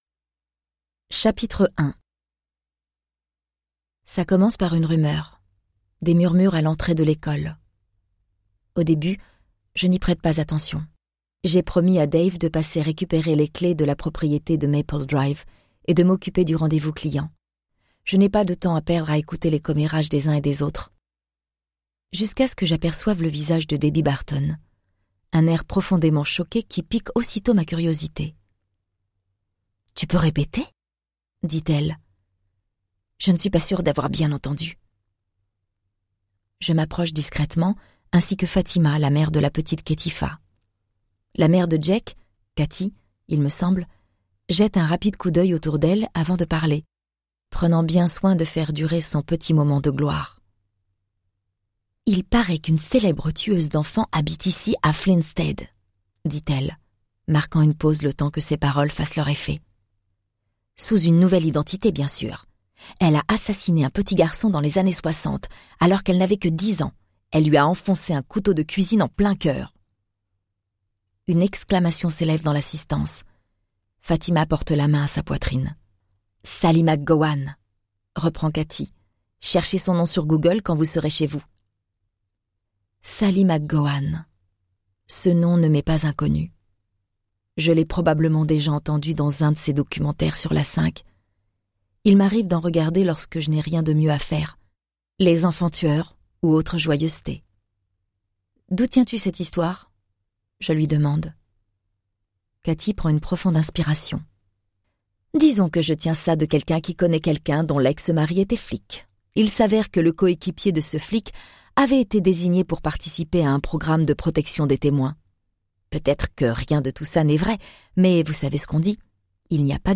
Click for an excerpt - La Rumeur de Lesley Kara